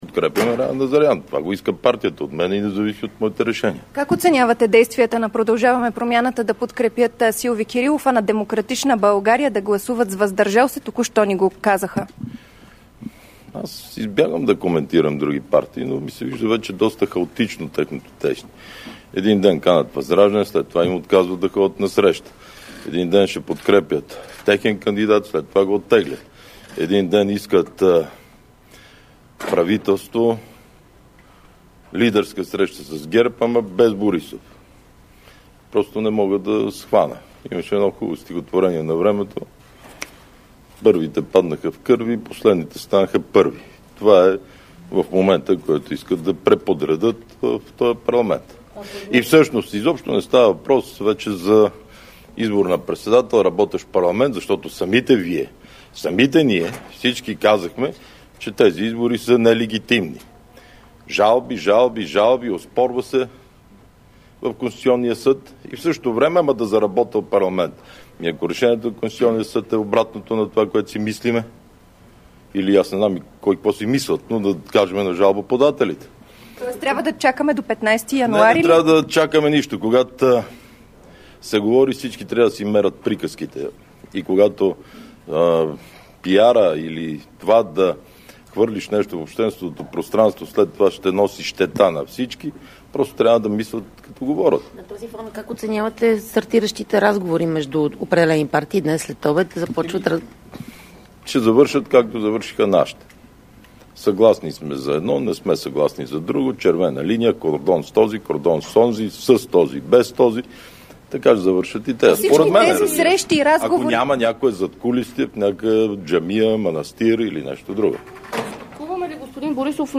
10.50 - Брифинг на лидера на ГЕРБ Бойко Борисов. - директно от мястото на събитието (Народното събрание )